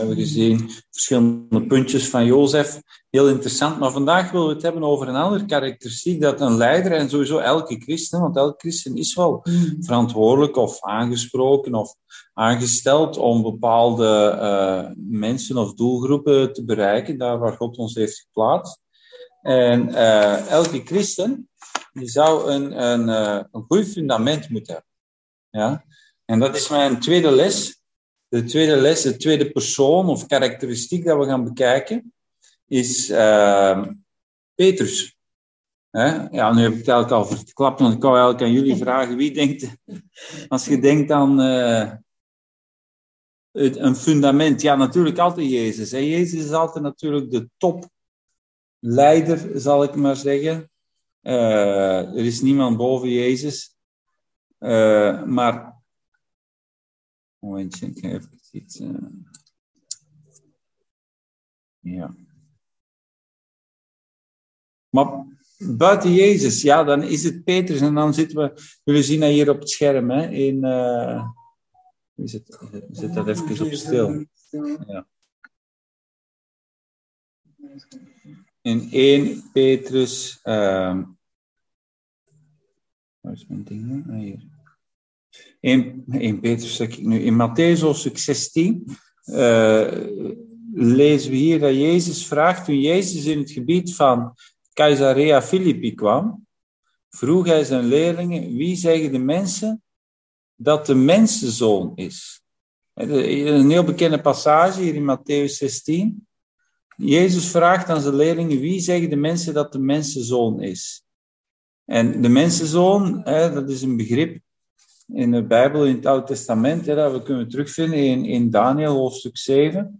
Bijbelstudie: leiderschap: fundament